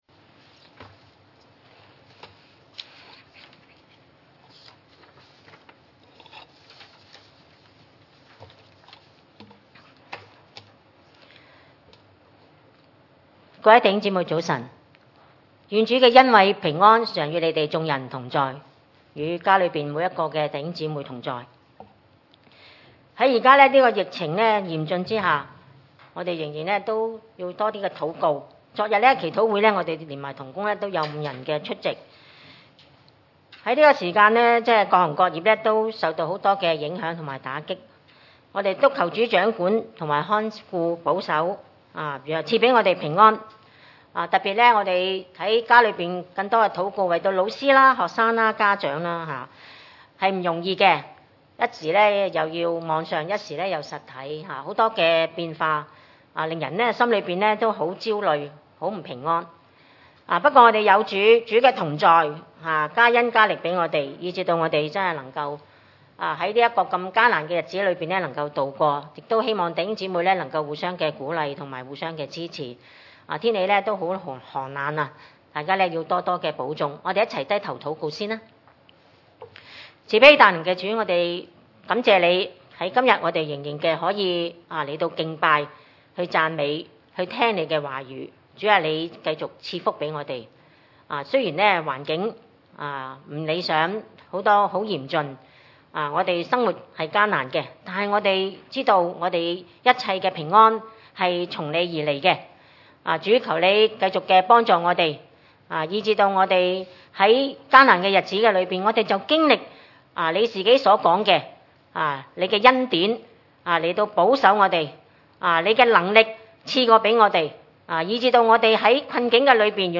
崇拜類別: 主日午堂崇拜